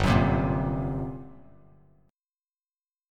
GmM11 chord